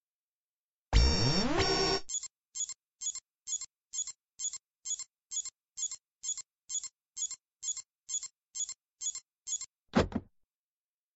268灯泡音效04.mp3